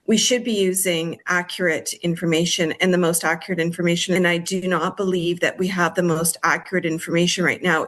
Mayor Olena Hankivsky raised concerns about moving forward without more accurate growth projections, particularly given potential impacts tied to Wesleyville development.